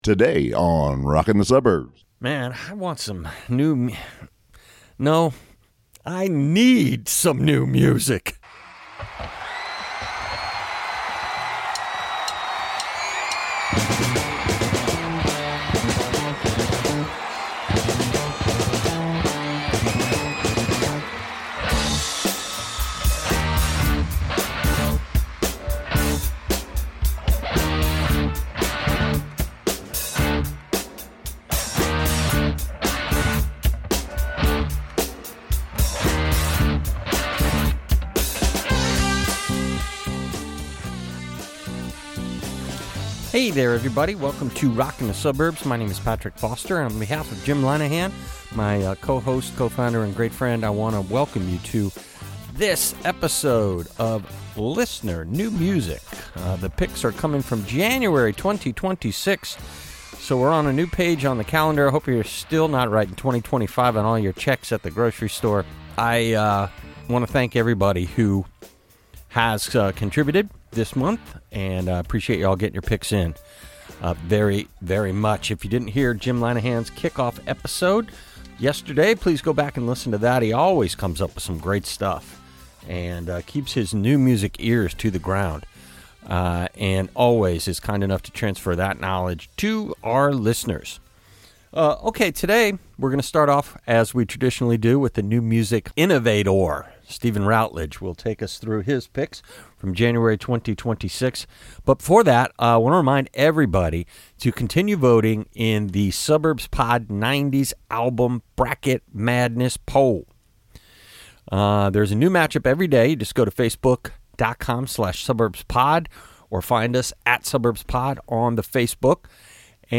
Theme music: "Ascension," originally by Quartjar, next covered by Frank Muffin and now re-done in a high-voltage version by Quartjar again!